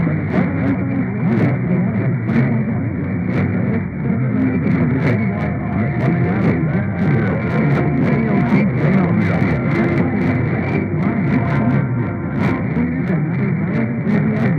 WAOC St. Augustine," jingle, and contemporary Christian music.